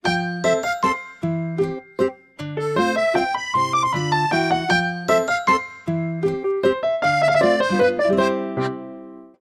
Kategori: Zil Sesleri